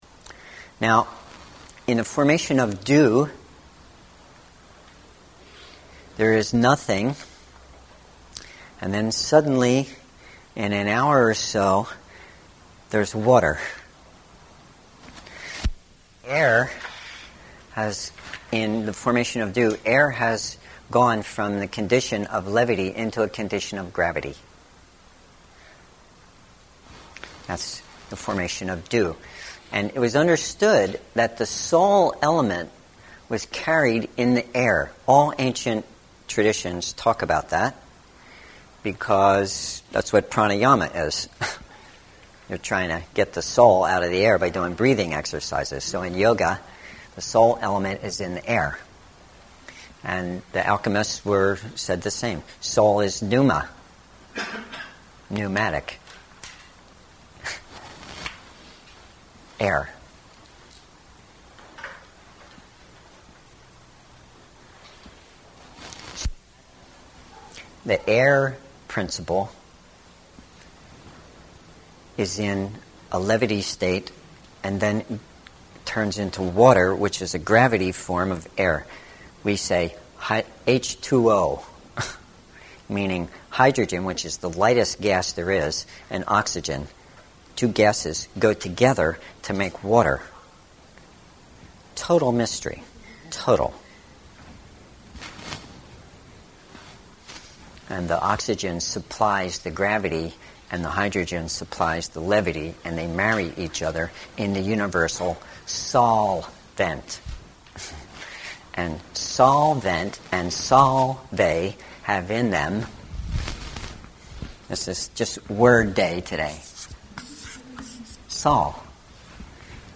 Listen to an E x c e r p t or the Full Version of this lecture in RealMedia format (get RealPlayer here) or